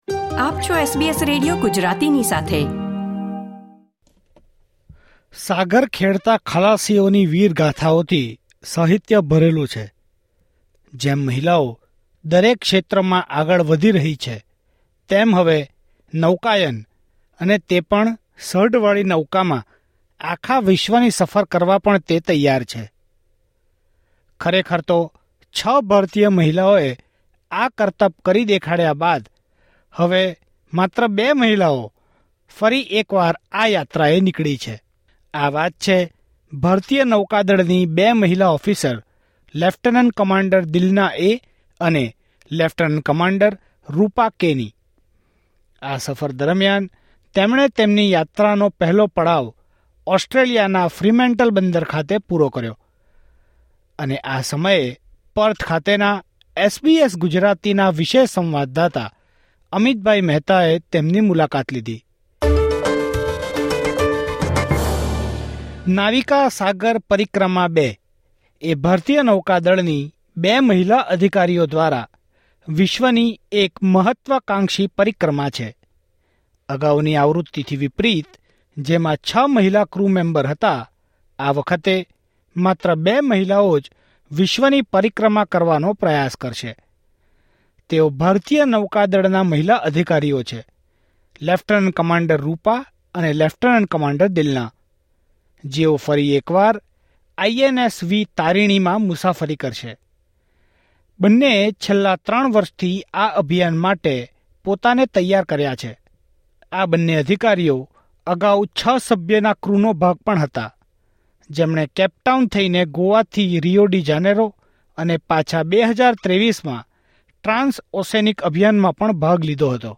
તેમનો સંવાદ માણિએ.